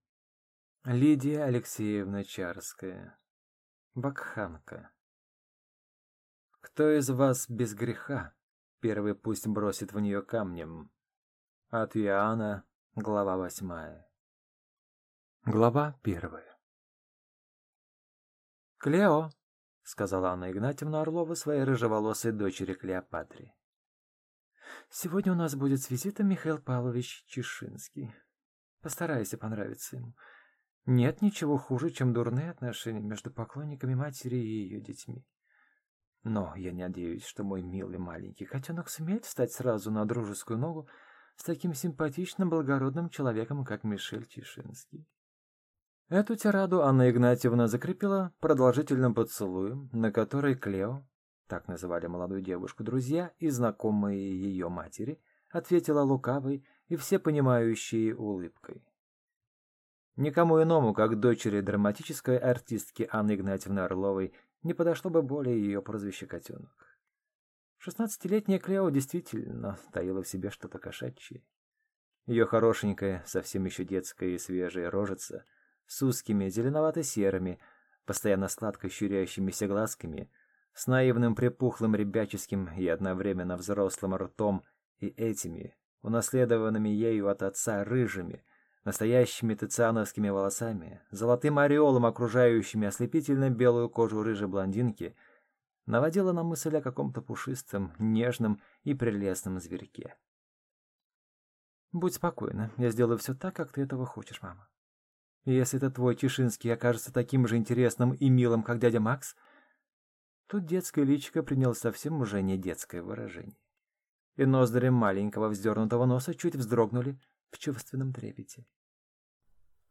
Аудиокнига Вакханка | Библиотека аудиокниг
Прослушать и бесплатно скачать фрагмент аудиокниги